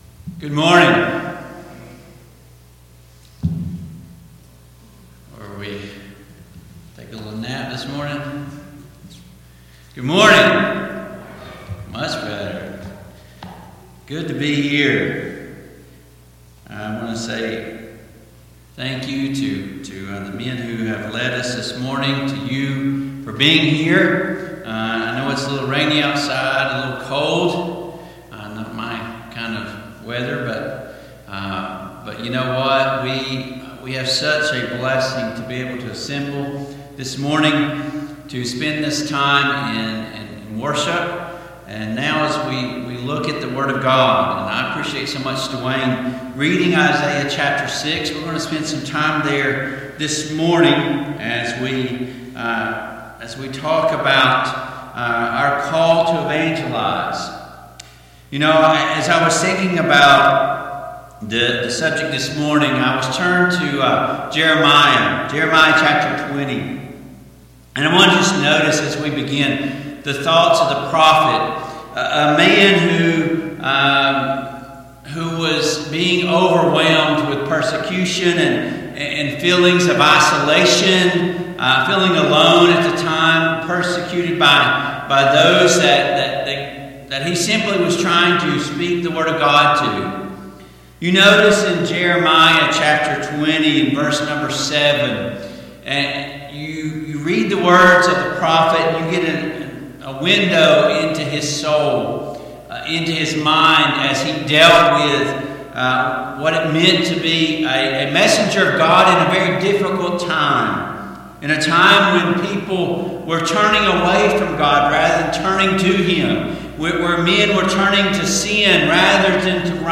Service Type: AM Worship Topics: Evangelism , The Gospel of Jesus Christ